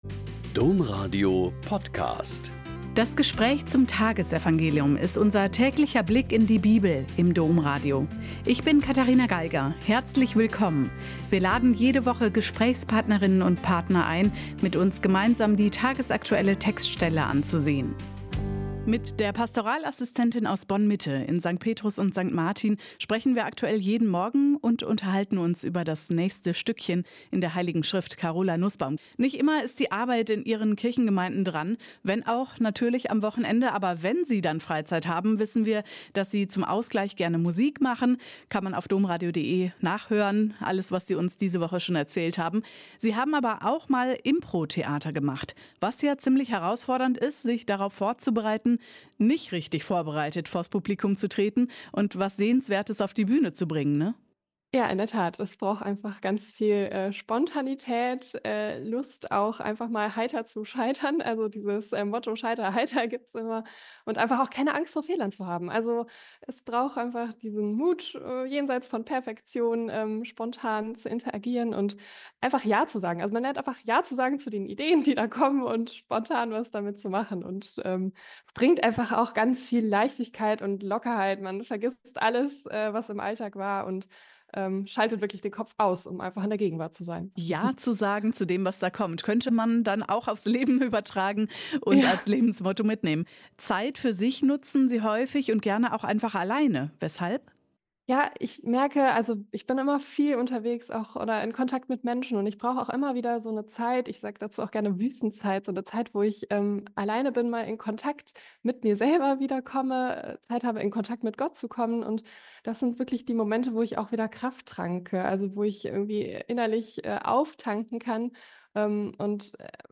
Mk 16,15-18 - Gespräch